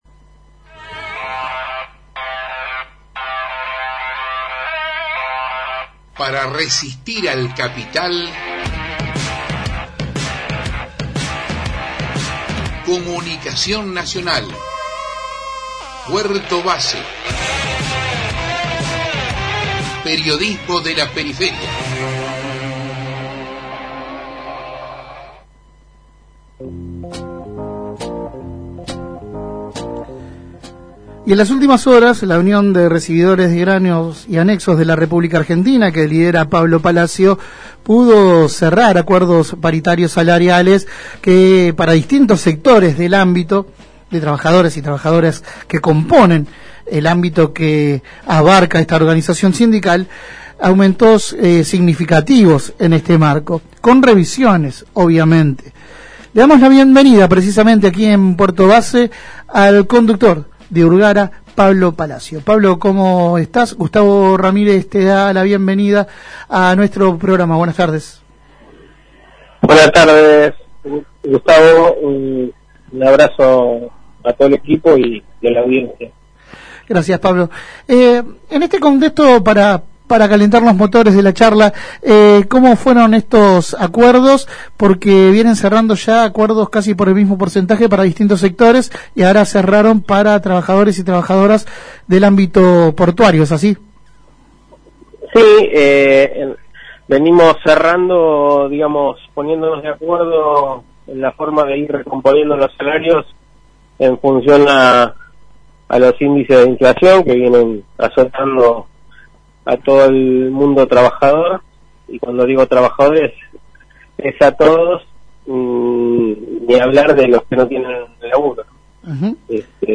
Compartimos la entrevista completa: 6/9/2022